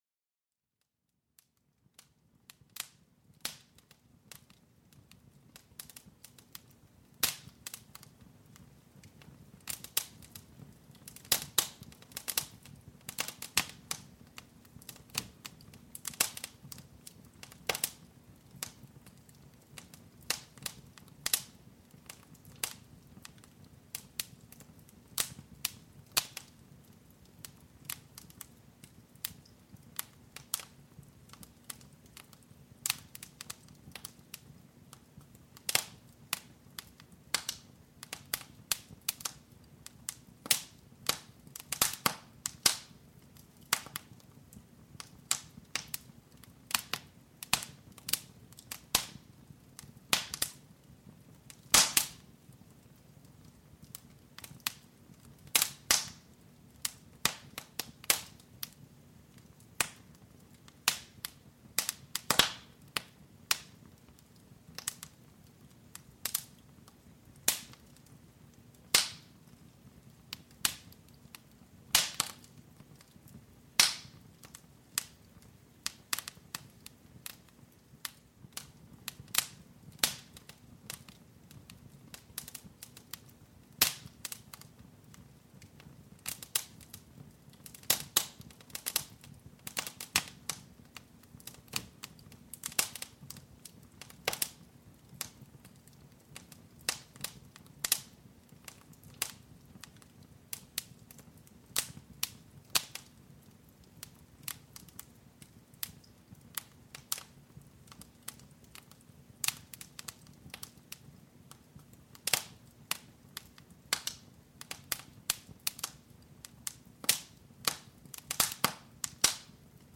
Crepitación del fuego: Calidez reconfortante para una relajación profunda